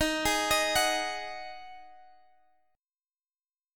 Listen to D#mbb5 strummed